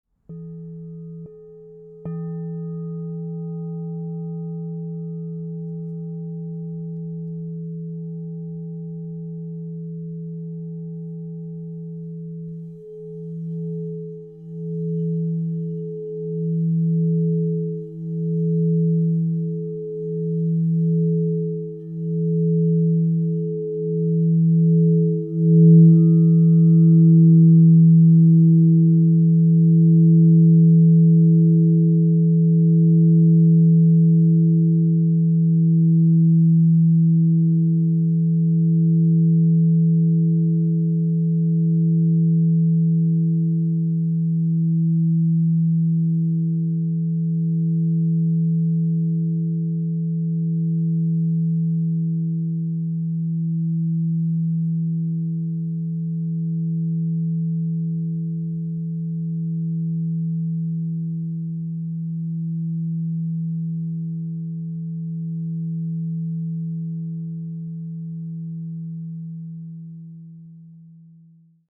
Violet Flame Aura 12″ E -10 Crystal Tones singing bowl
Enhance your journey with 12″ Crystal Tones® True Tone alchemy singing bowl made with Violet Flame Aura in the key of E -10.
432Hz (-), 440Hz (TrueTone)